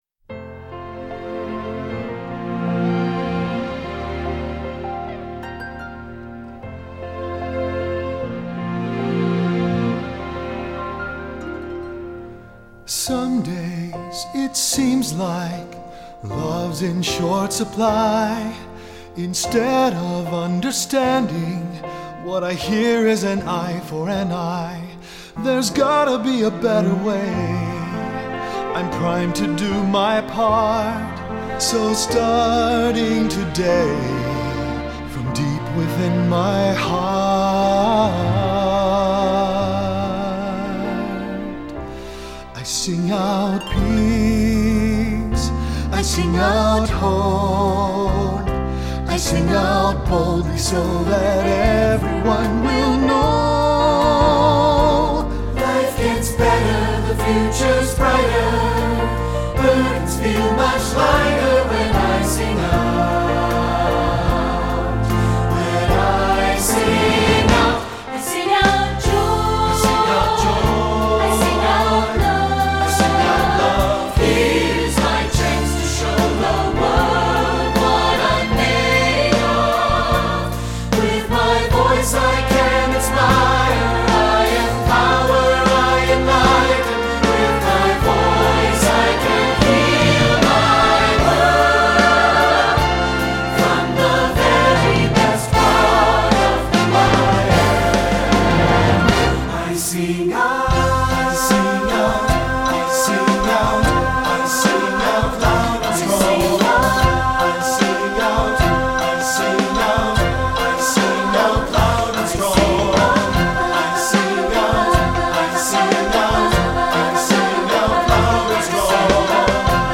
instructional, secular choral
SATB